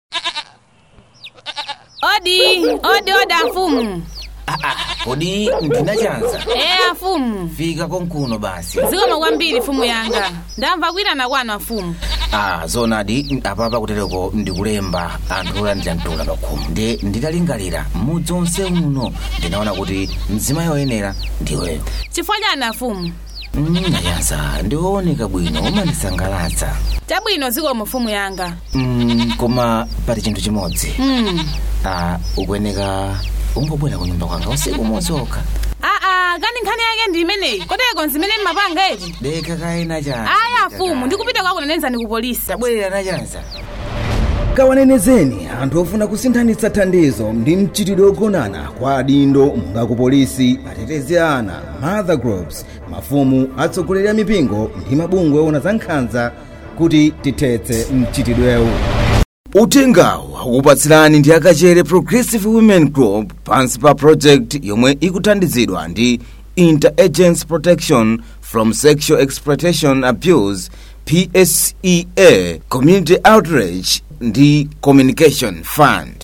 KPWG_Radio Jingle Chichewa 1 - ICVA
KPWG_Radio-Jingle-Chichewa-1.mp3